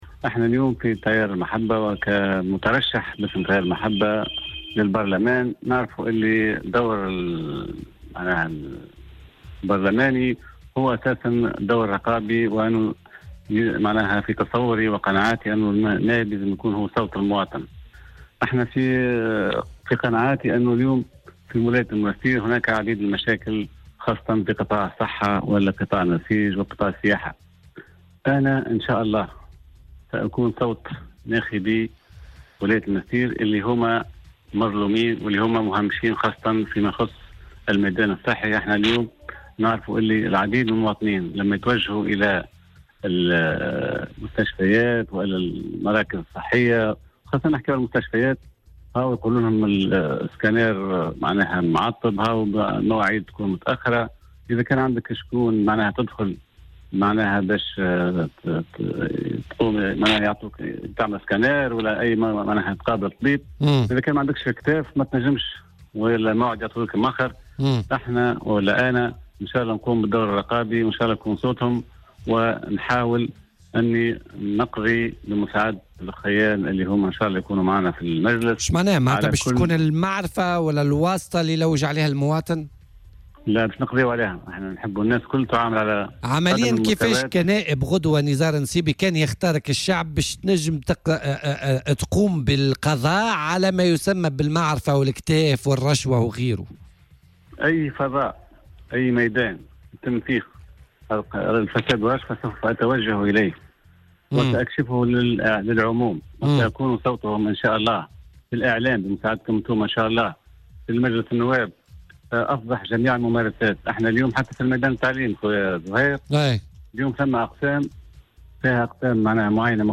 وأضاف في مداخلة له اليوم في برنامج "بوليتيكا" على "الجوهرة أف أم" أنه سيعمل على محاربة الفساد وسيتطرق إلى أهم المشاكل التي تهم الجهة خاصة في قطاعات النسيج والسياحة والصحة.